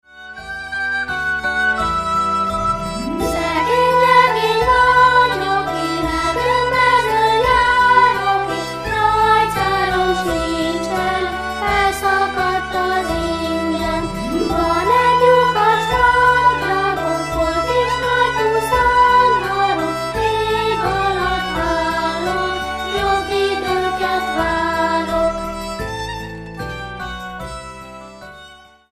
kisiskolások adják elő.